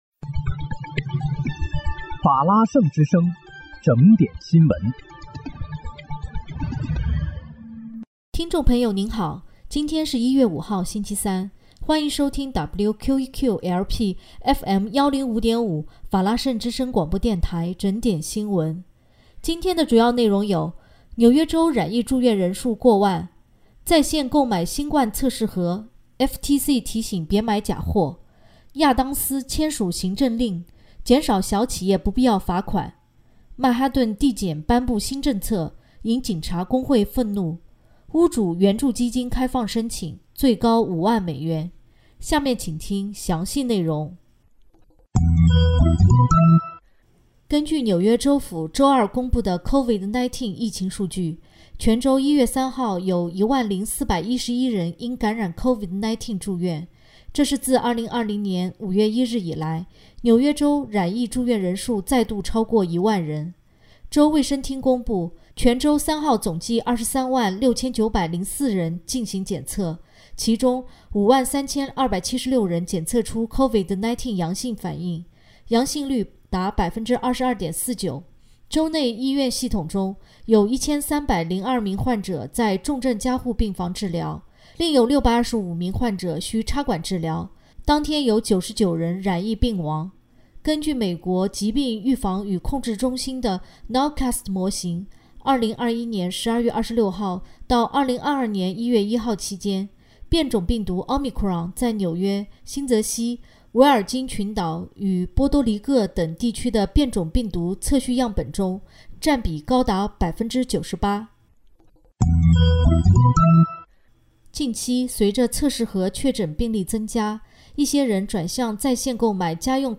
1月5日(星期三）纽约整点新闻